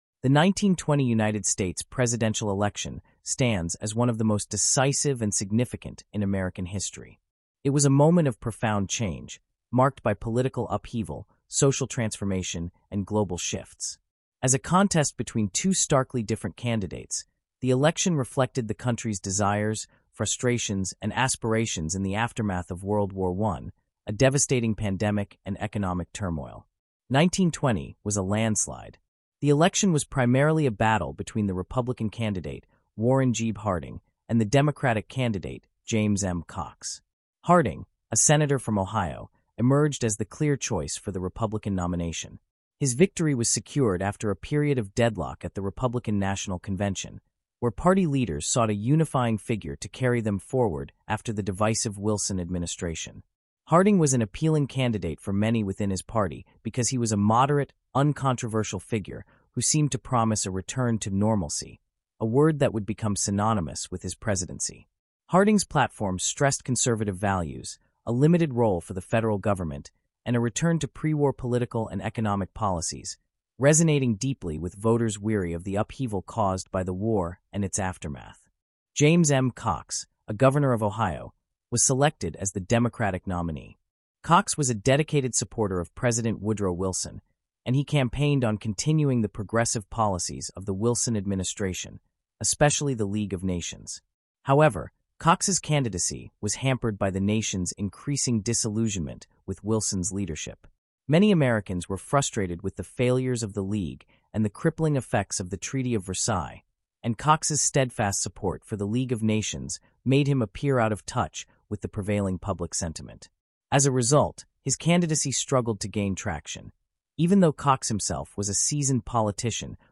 Disclosure: This podcast includes content generated using an AI voice model. While efforts were made to ensure accuracy and clarity, some voices may not represent real individuals.